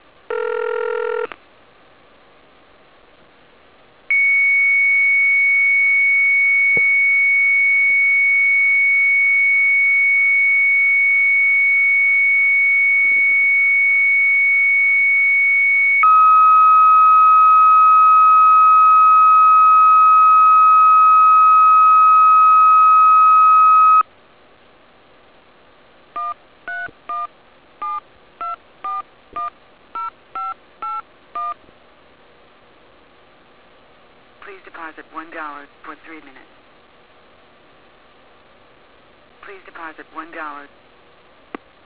Recording of an Elcotel phone either than a Series 5 - .WAV/328KB